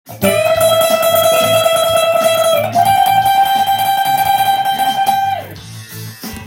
全てAmキーの曲で使えるフレーズになります。
トレモロを合わせてハイブリットフレーズです。